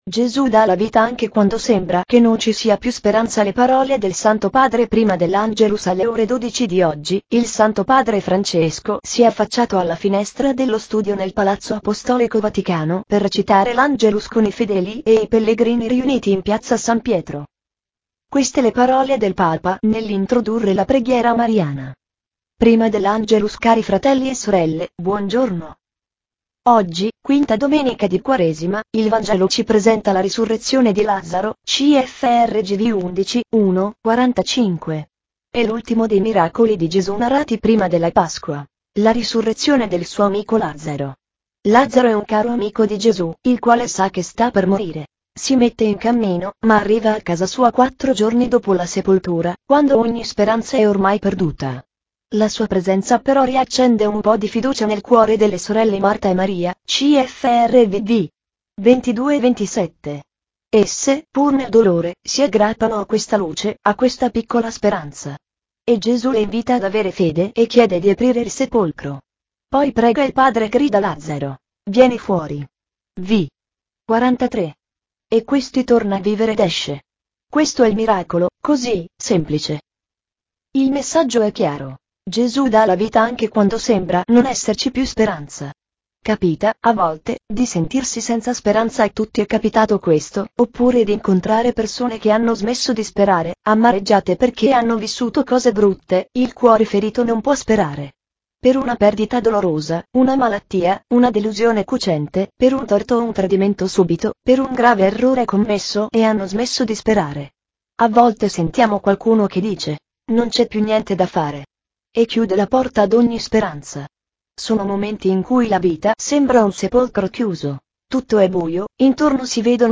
Le parole del Santo Padre prima dell’Angelus
Alle ore 12 di oggi, il Santo Padre Francesco si è affacciato alla finestra dello studio nel Palazzo Apostolico Vaticano per recitare l’Angelus con i fedeli e i pellegrini riuniti in Piazza San Pietro.